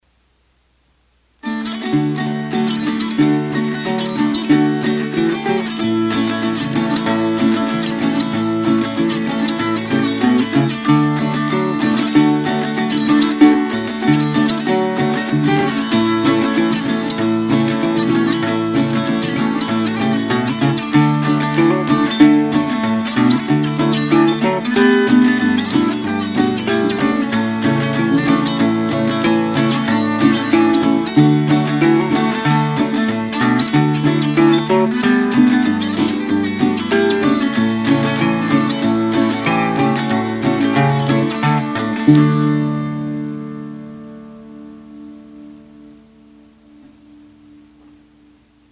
Gardners Dulcimer Shop - About our Dulcimers
These dimensions give our instruments a full rich tone.
• Listen to samples of our Dulcimers being played one at a time to hear 2 different songs: